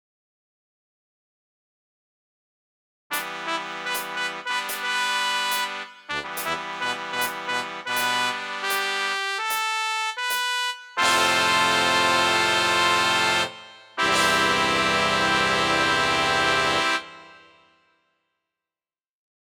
またファンファーレを作ってみた 前記事と同じくwalkbandを使用しています。 タイトル：cider 前回に引き続き、作成時に飲んでいたものをタイトルに。
またファンファーレを作ってみた